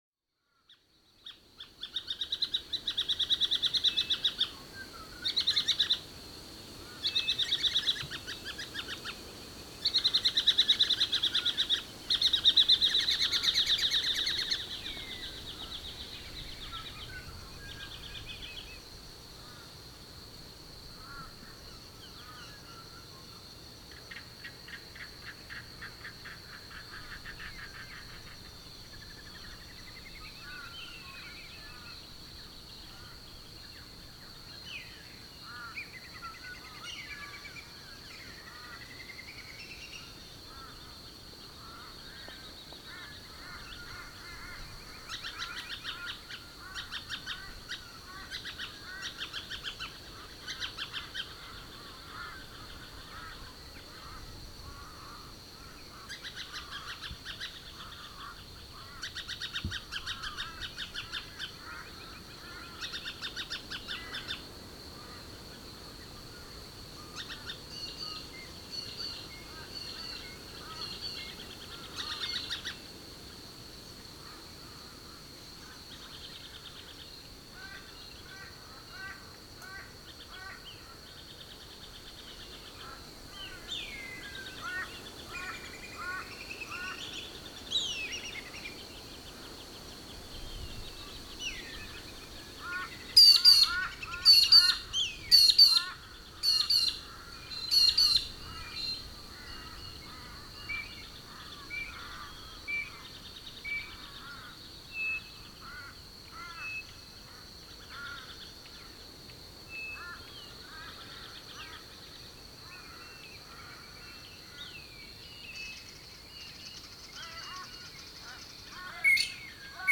7am…Lakeside bird chorus etc – NT
As the morning progresses … so does the bird chorus of both land & water birds.   Also … with increasing wind … you may also hear occasional branches squeaking & waves of rustling tree leaves.
r05_7am-lakeside-bird-chorus-etc.mp3